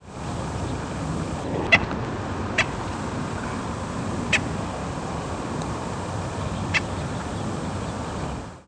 Boat-tailed Grackle diurnal flight calls
Male in flight.